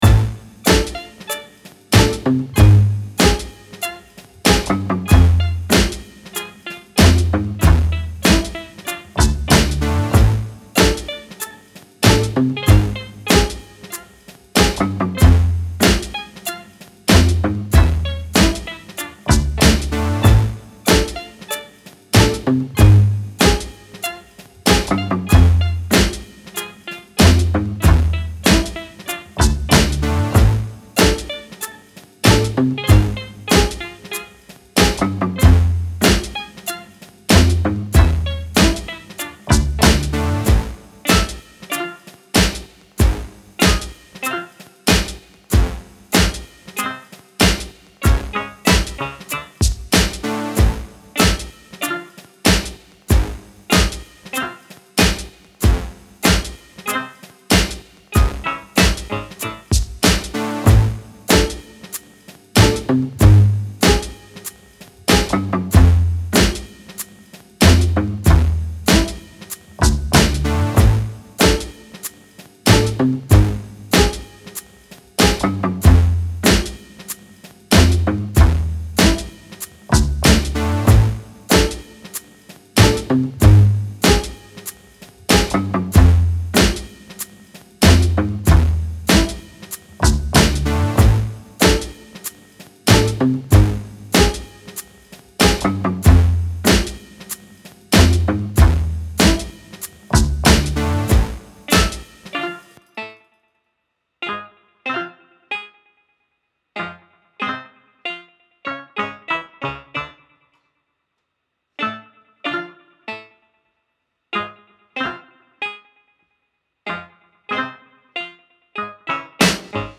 Peculiar slow leftfield beat with minimal beats and bass.